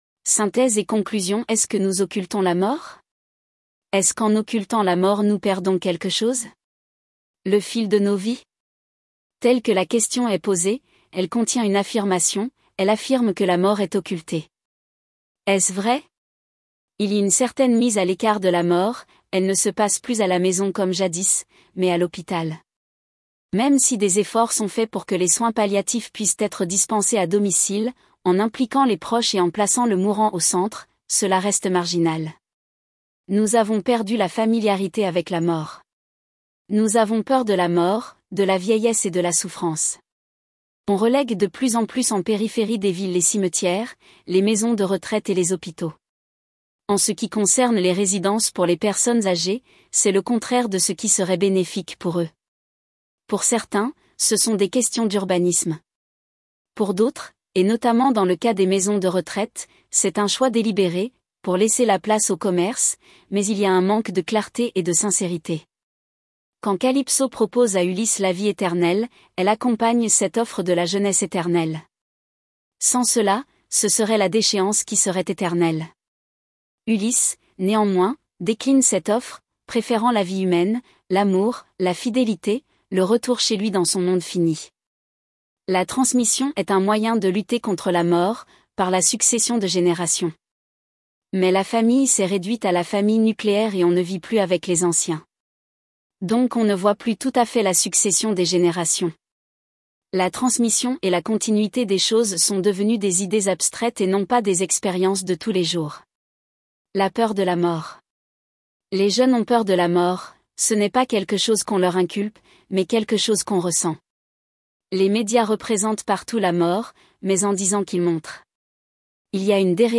Conférences et cafés-philo, Orléans